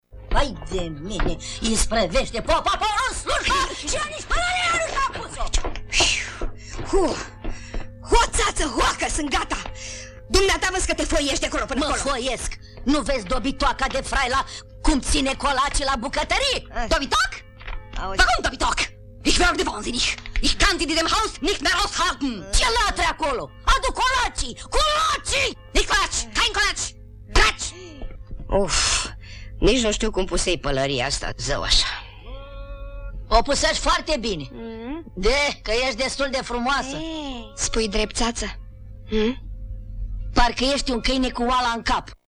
Tamara Buciuceanu Botez a fost Coana Chiriţa, profesoara de matematică Isoscel din filmele Declaraţie de Dragoste şi Liceenii, dar şi Aneta din Cuibul de viespi, un film în care i-au dat replica, între alţii, Ileana Stana Ionescu şi Coca Andronescu: